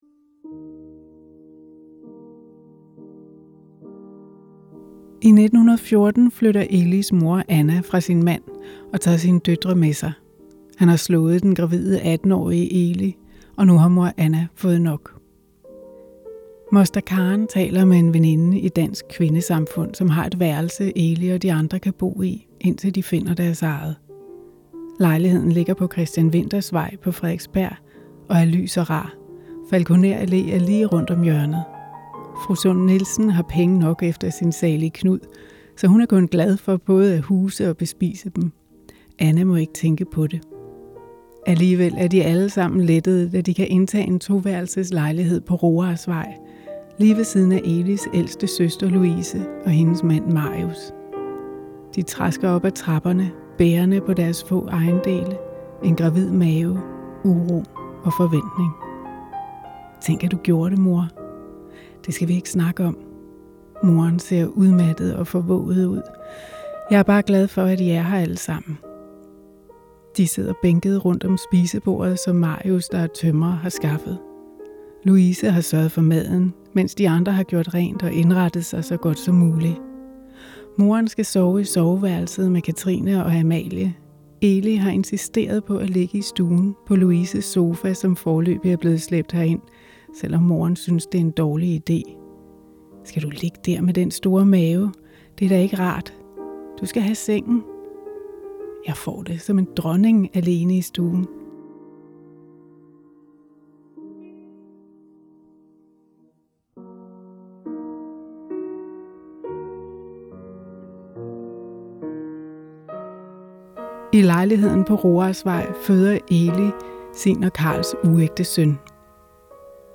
læse et uddrag fra romanen, der foregår på Roarsvej 6.